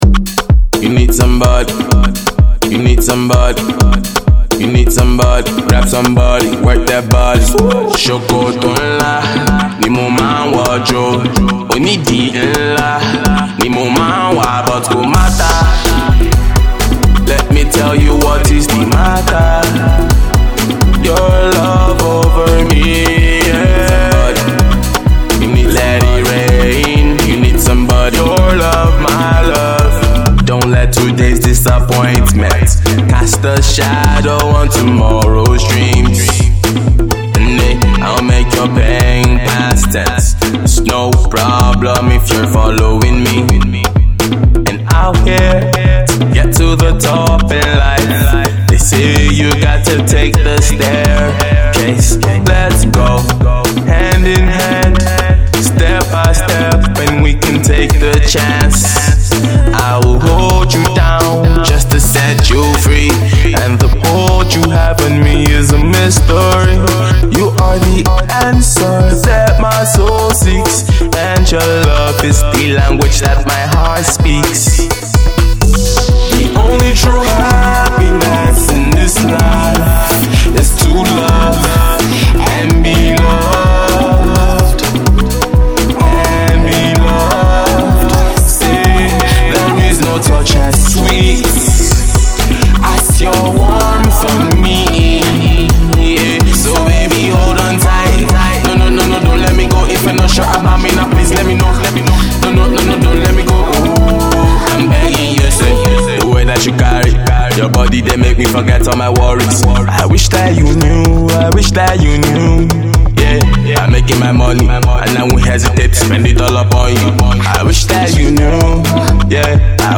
This song has a groovy feel to it.